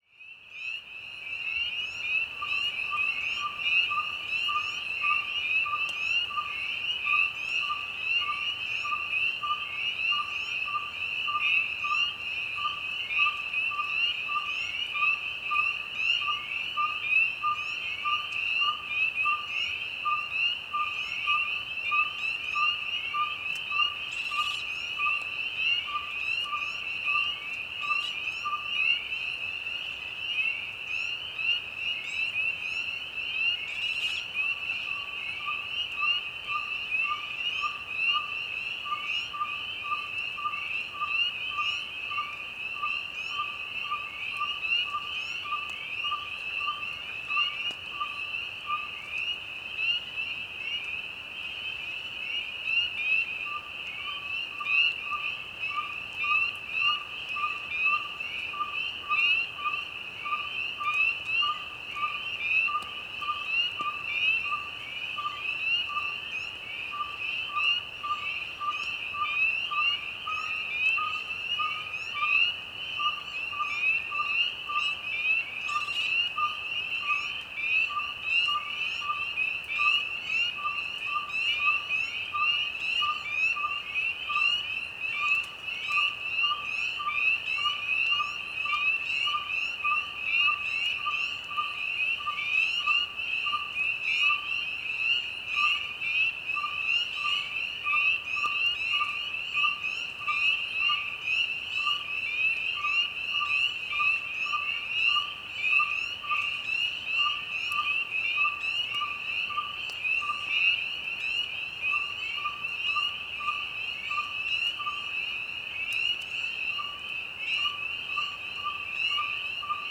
Petite Nyctale – Aegolius acadicus
Chant Chant d'une Petite Nyctale (mâle?) pendant la nuit. Un choeur de Rainette crucifère tapisse l'ambiance et un couple de Plongeon huard chante plus loin sur le lac. On peut aussi y entendre la Grenouille des bois. Lac Caron, Saint-Marcellin, QC, 48°21'17.7"N 68°19'41.1"W. 22 mai 2019. nuit.